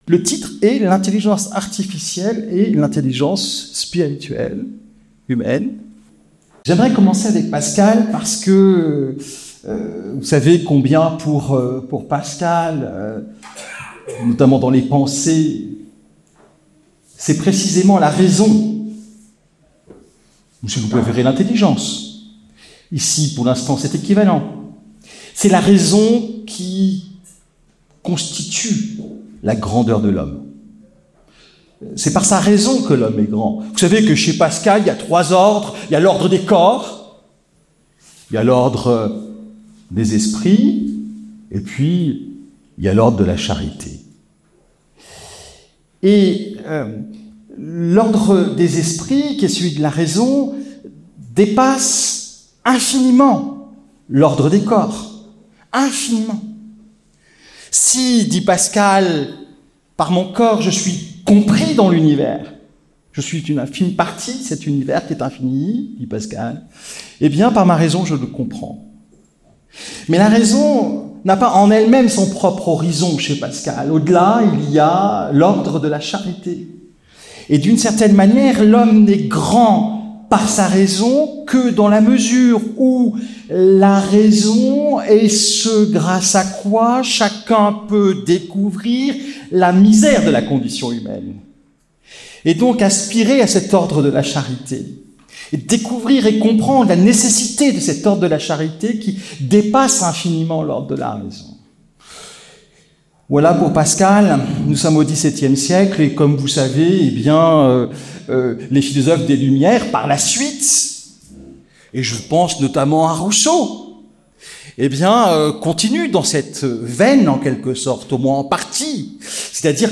Le Puy-en-Velay.